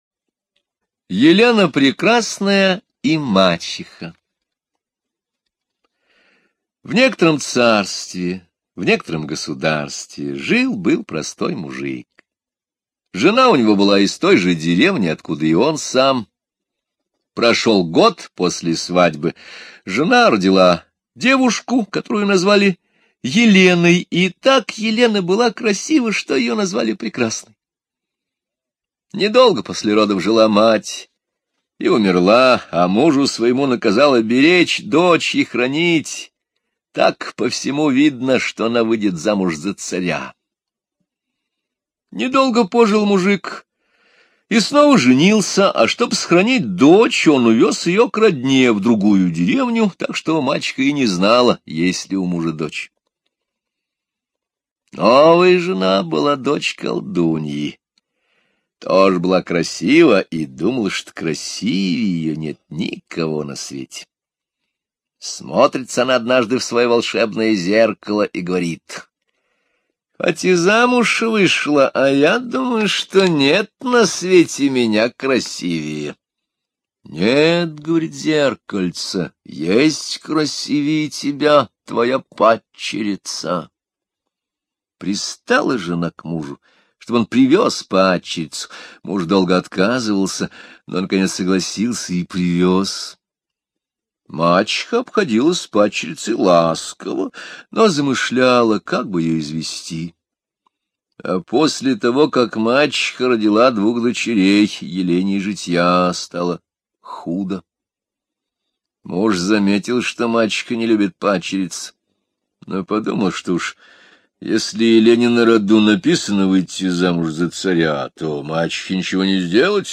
Русские народные аудиосказки